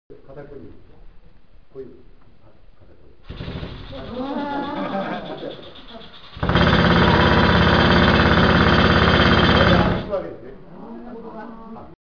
■模擬坑道
★削岩機の音